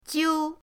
jiu1.mp3